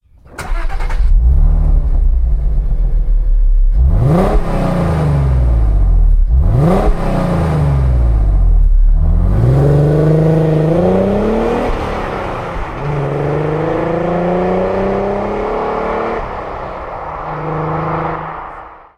Звук двигателя Porsche Cayenne